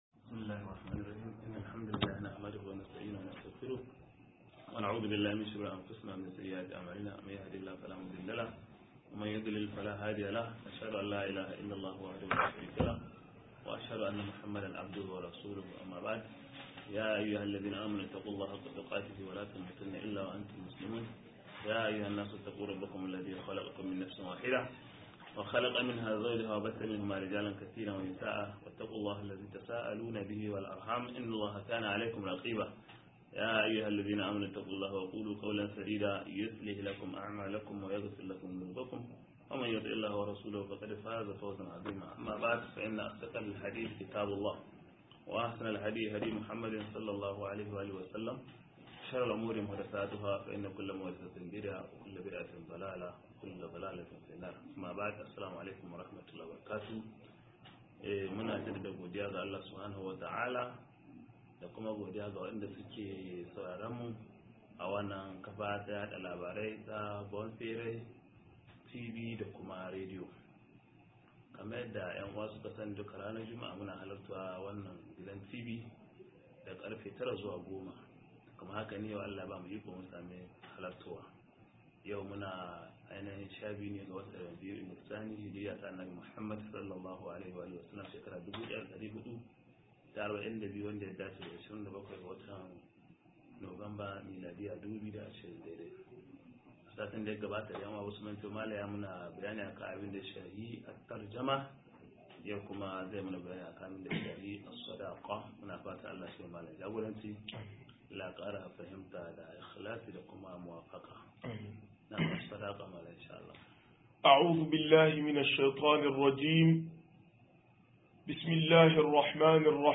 75 - MUHADARA